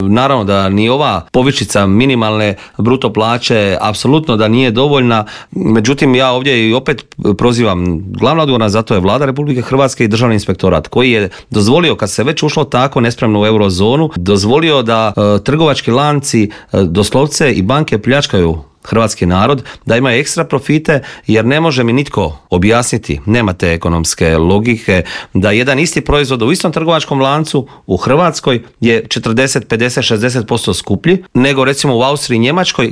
O ovogodišnjoj obljetnici i brojnim drugim političkim aktualnostima u Intervjuu Media servisa razgovarali smo s predsjednikom Hrvatskih suverenista Marijanom Pavličekom koji je poručio: "Čovjek se naježi kada vidi sve te mlade ljude koji idu prema Vukovaru."